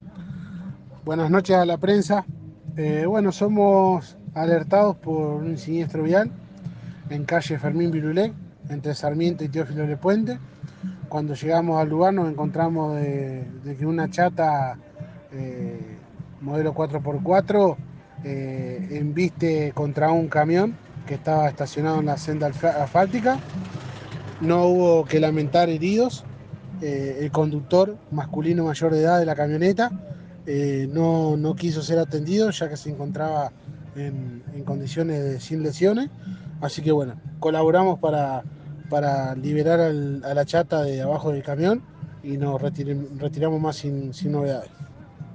EL INFORME DE BOMBEROS VOLUNTARIOS